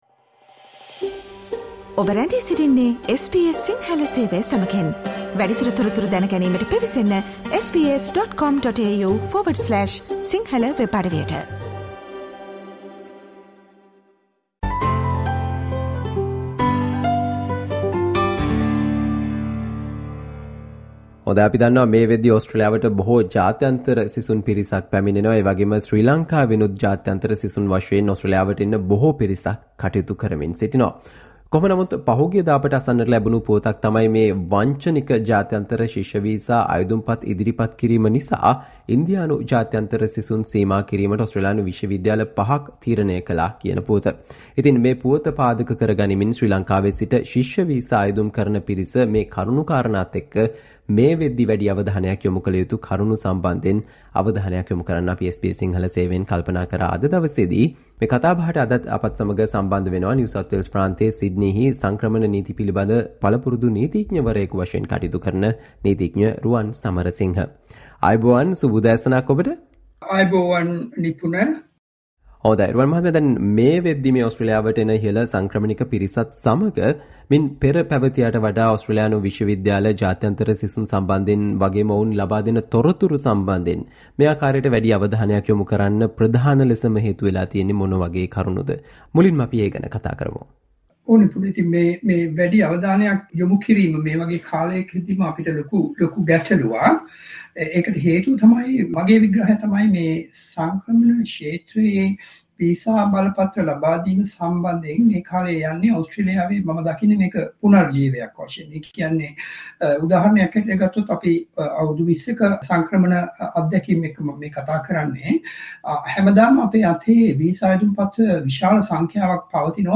SBS Sinhala discussion about the Consequences you will face by applying for international student visa to Australia, with fraudulent documents